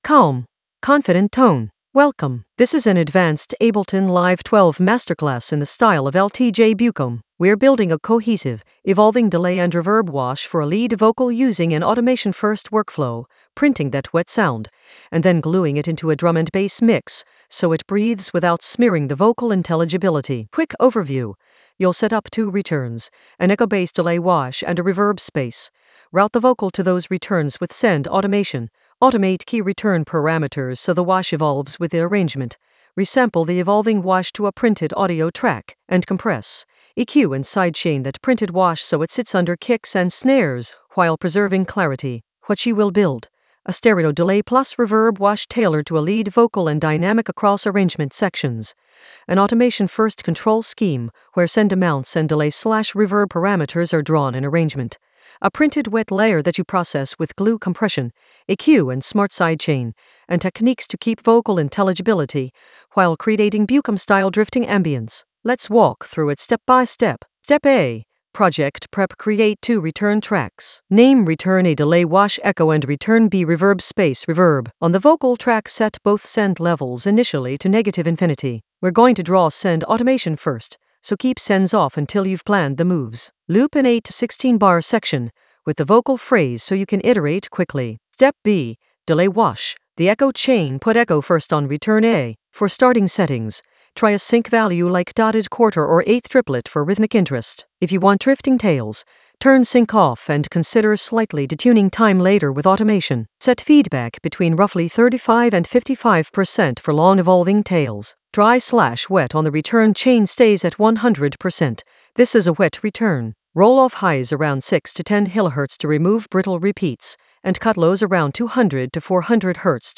An AI-generated advanced Ableton lesson focused on LTJ Bukem masterclass: glue the delay wash in Ableton Live 12 with automation-first workflow in the Vocals area of drum and bass production.
Narrated lesson audio
The voice track includes the tutorial plus extra teacher commentary.